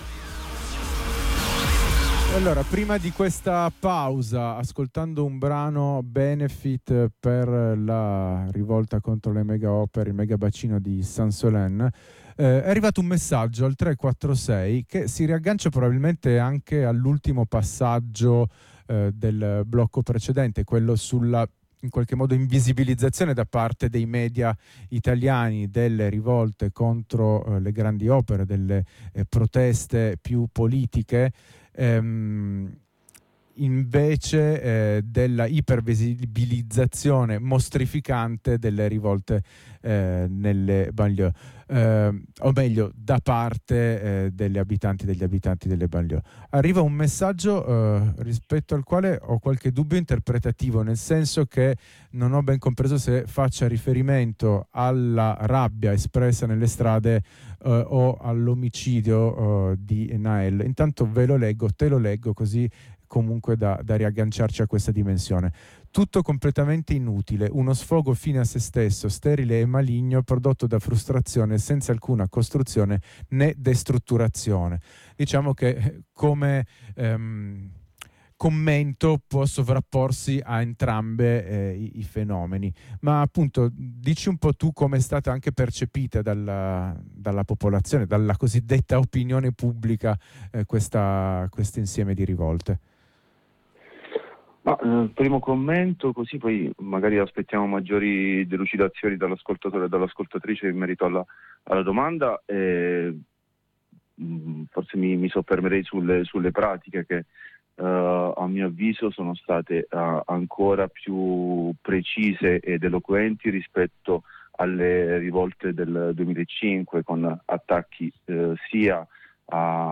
Estratti dalla puntata del 10 luglio 2023 di Bello Come Una Prigione Che Brucia In diretta con un compagno da Marsiglia cerchiamo di approfondire alcuni aspetti che hanno caratterizzato le rivolte successive all’omicidio di Nahel, con un focus specifico sull’approccio e le tecniche repressive messe in atto.